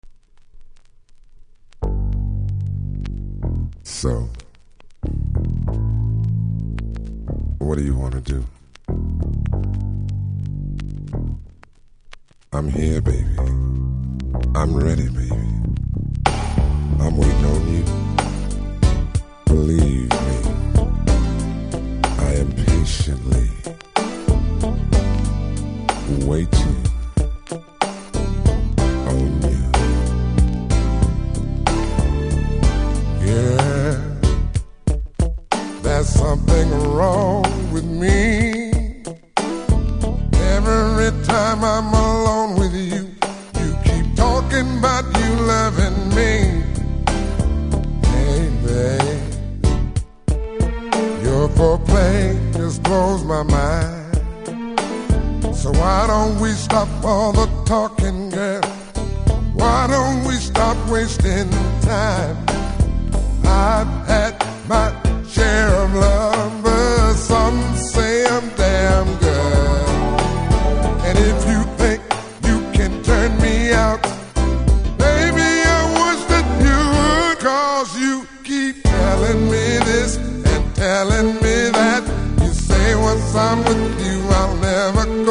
見た目キズも少なく良好ですが序盤の語り部分にノイズ感じますので試聴で確認下さい。